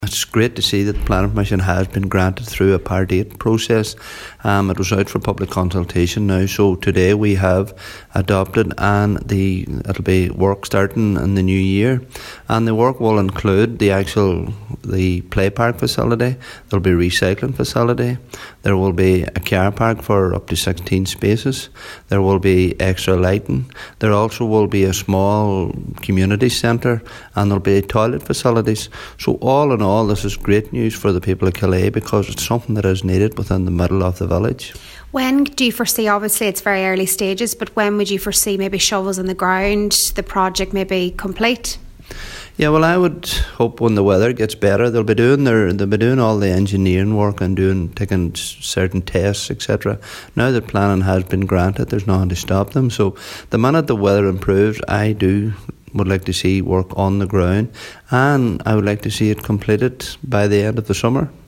Local Cllr Paul Canning says now that planning has been granted, there’s nothing stopping the project being complete by the end of 2020: